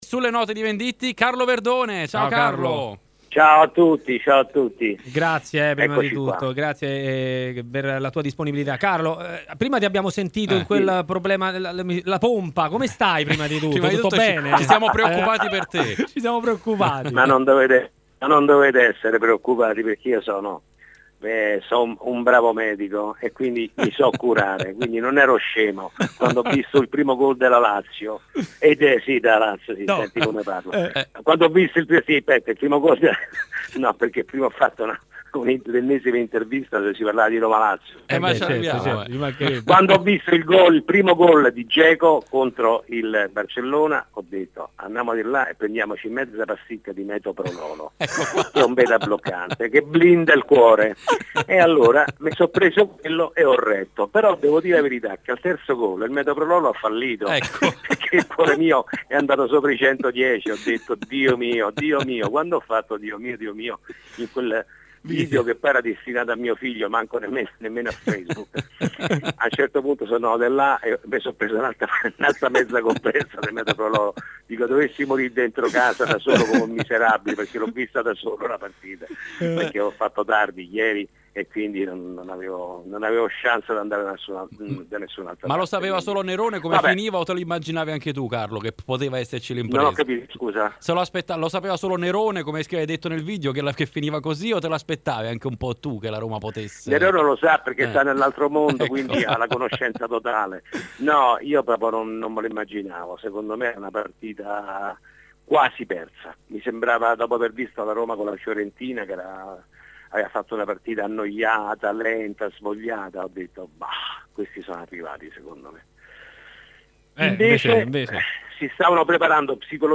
In diretta a 'Maracanà' su 'RMC Sport' continua a tenere banco la Roma di Di Francesco, che ieri sera si è qualificata in semifinale di Champions League. Per parlare dell'impresa giallorossa, è intervenuto in diretta l'attore Carlo Verdone: